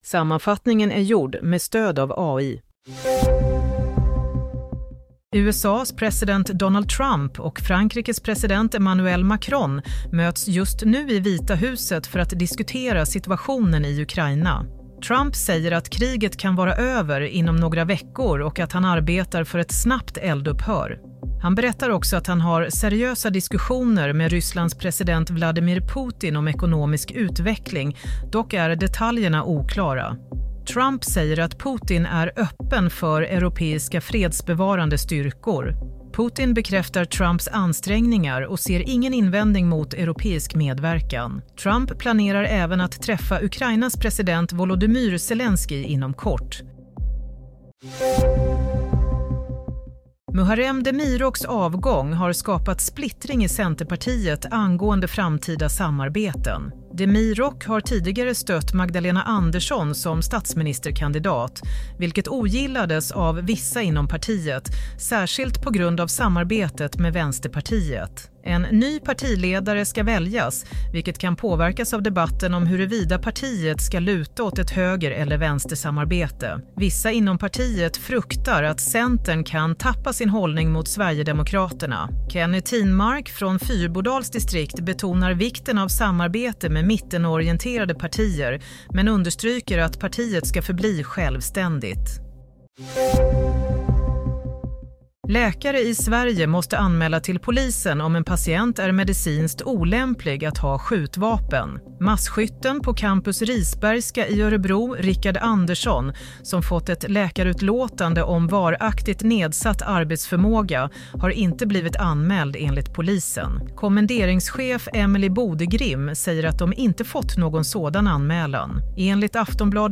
Nyhetssammanfattning – 24 februari 22:00